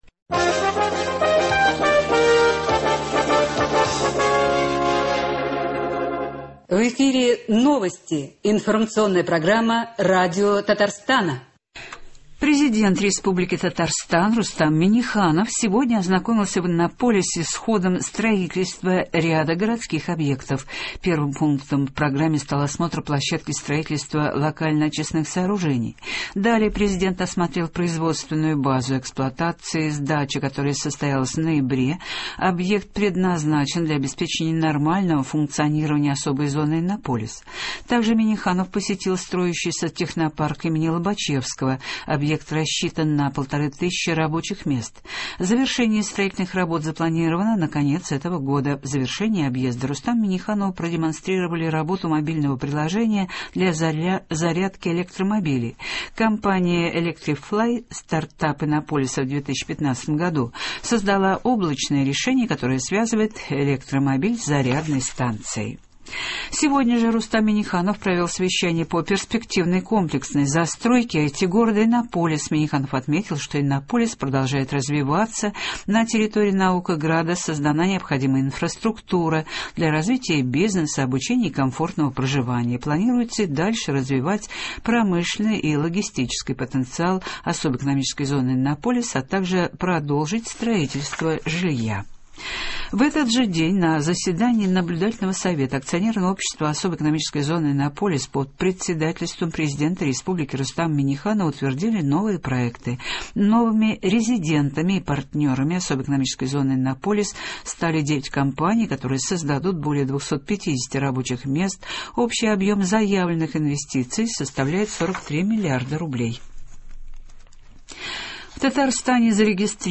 Утренний выпуск.